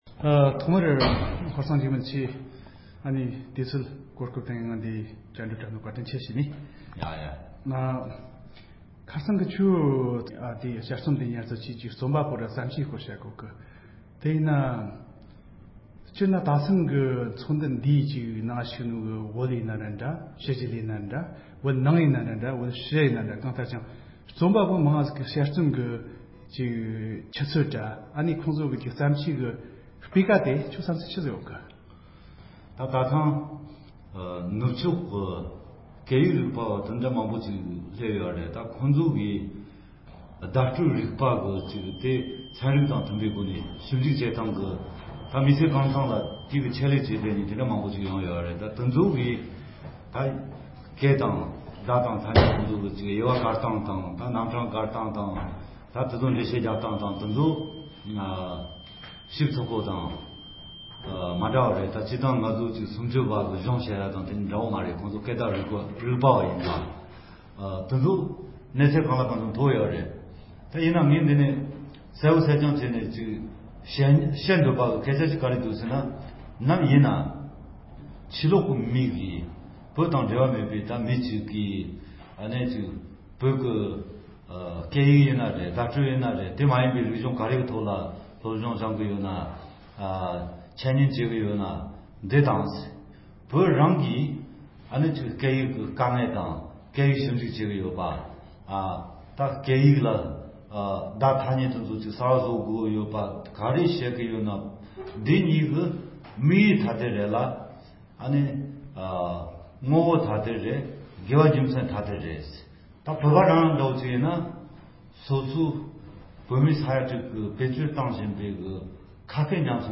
བཅར་འདྲི་